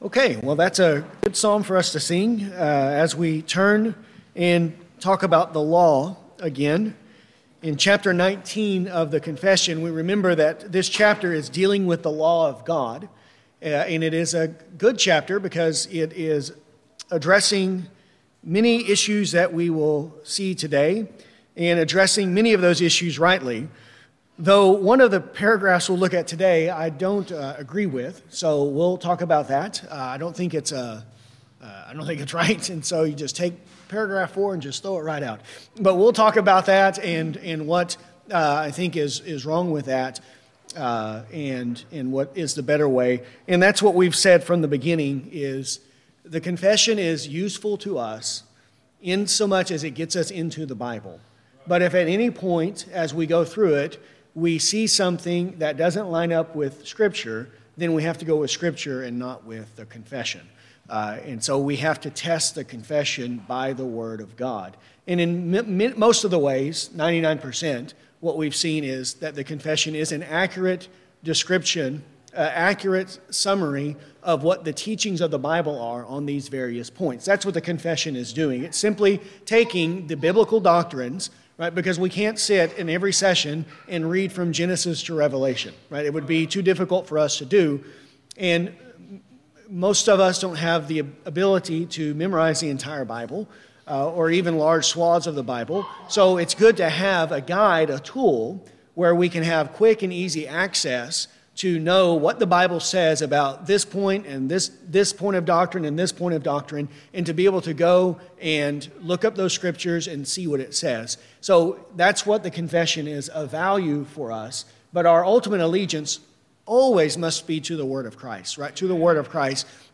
This lesson covers Paragraph 19.3. To follow along while listening, use the link below to view a copy of the confession.